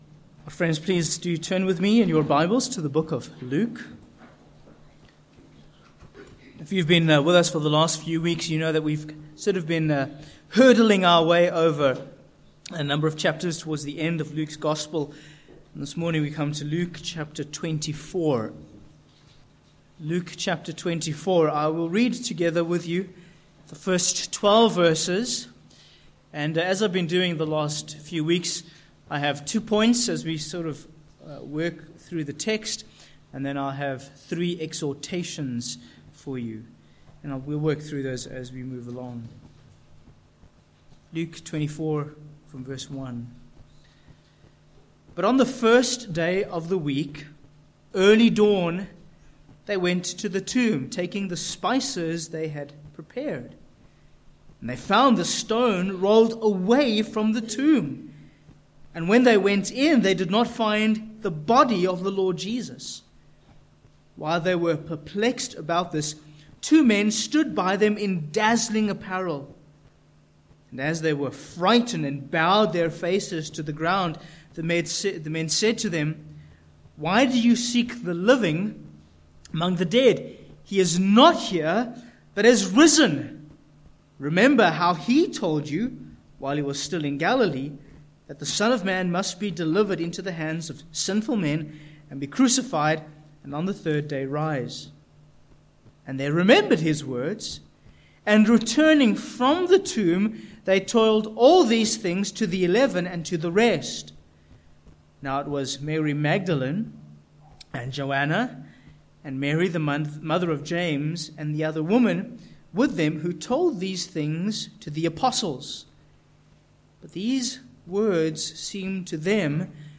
Sermon points: 1. Death Defeated v1-7